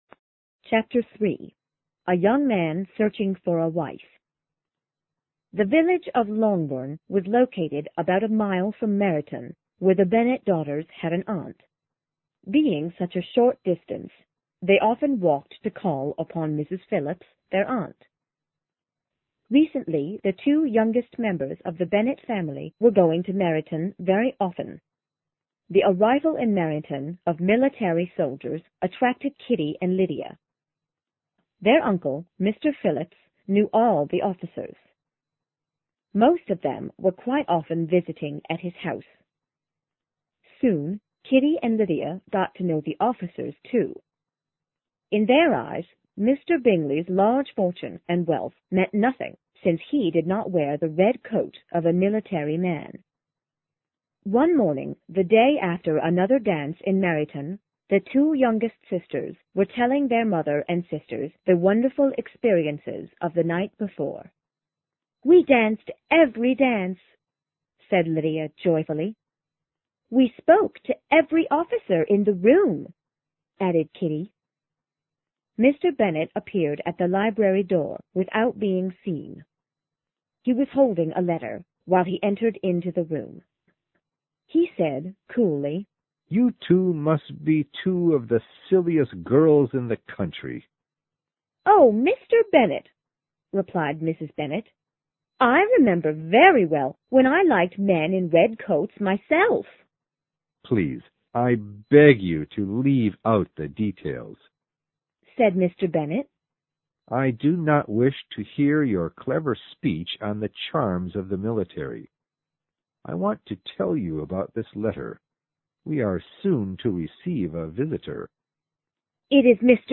有声名著之傲慢与偏见 Chapter3 听力文件下载—在线英语听力室